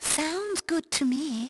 Madame Clairvoya voice clip